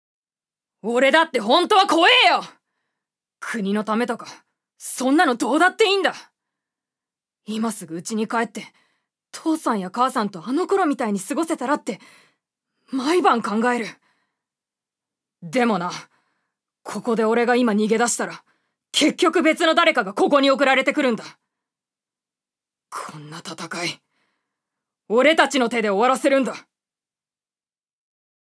女性タレント
セリフ６